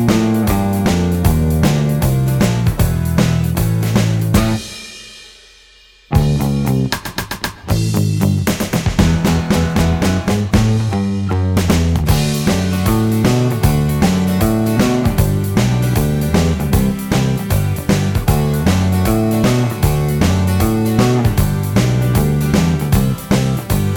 No Guitar Solo Rock 2:43 Buy £1.50